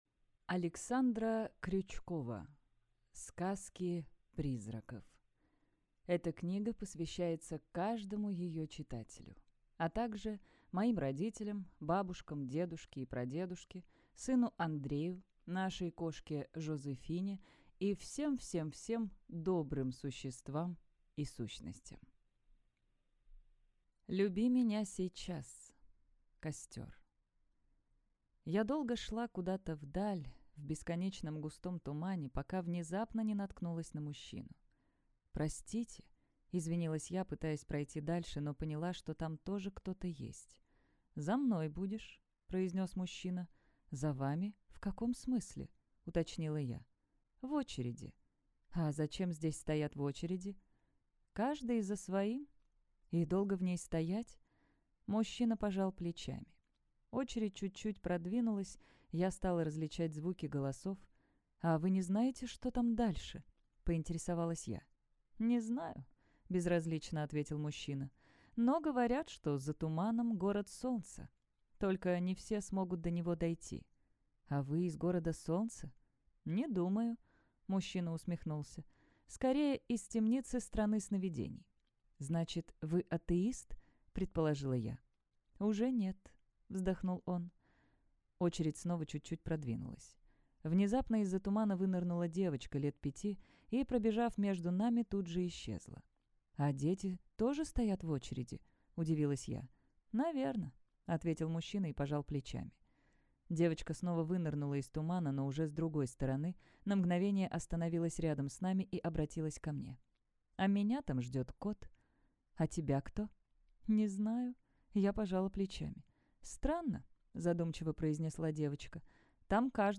Аудиокнига Сказки призраков. Премия имени Эдгара По. Игра в Иную Реальность | Библиотека аудиокниг